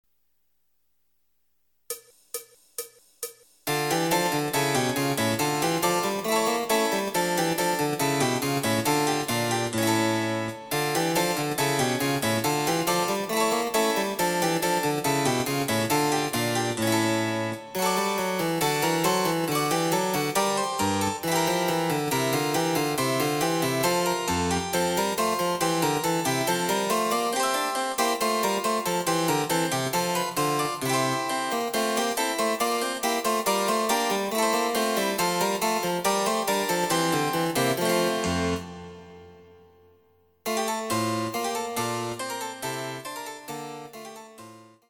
試聴ファイル（伴奏）
ソナタ　第３番　ニ短調
デジタルサンプリング音源使用
※フルート奏者による演奏例は収録されていません。